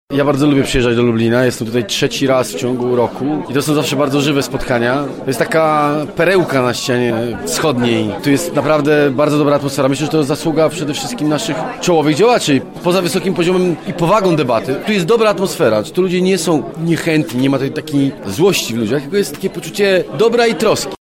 Ten ostatni podsumował piątkowe spotkanie: